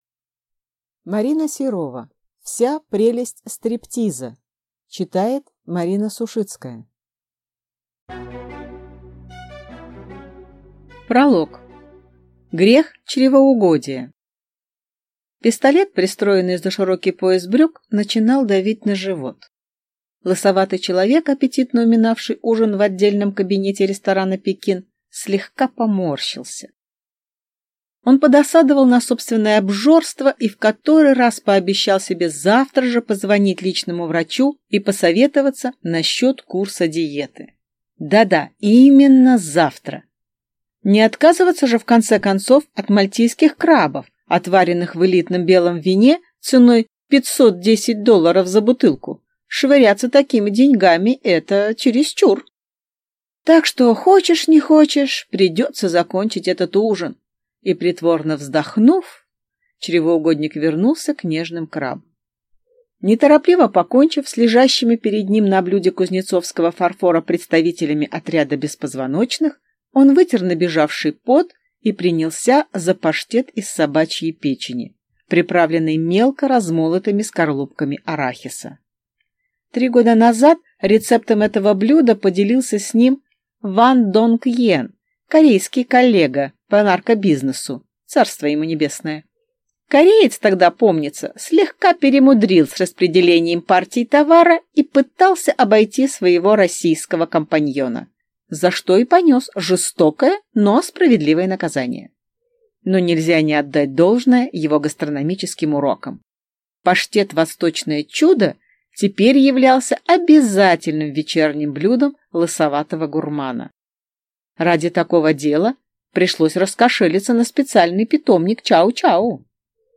Аудиокнига Вся прелесть стриптиза | Библиотека аудиокниг